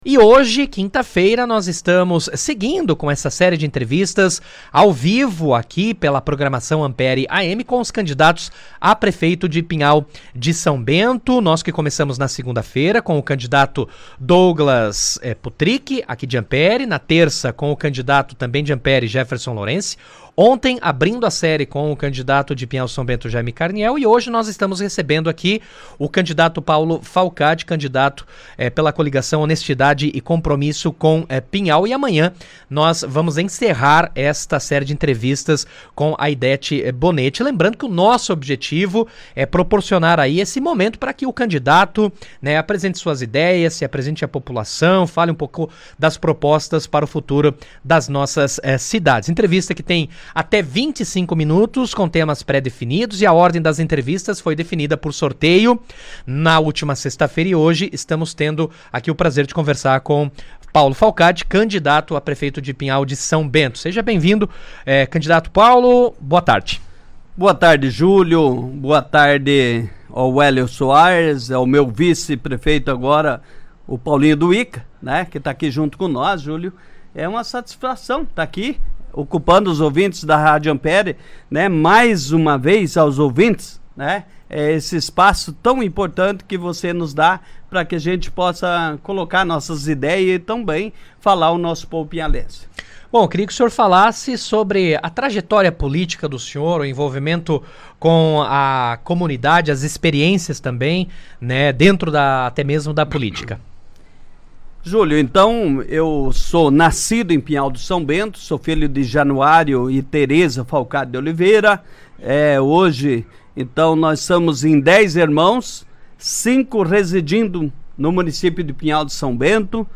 Jornalismo da Rádio Ampére AM entrevista candidatos a Prefeito de Pinhal de São Bento - Rádio Ampere AM